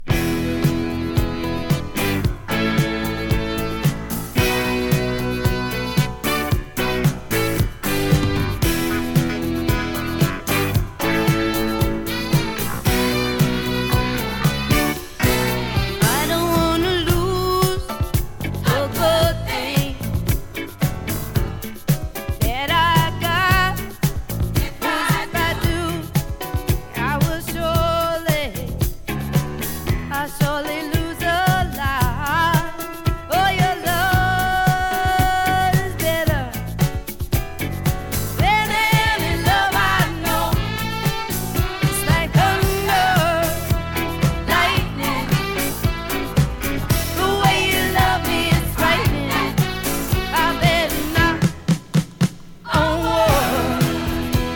カヴァーから威勢よく幕開けるアルバム。
をグルーヴィーにカヴァーも。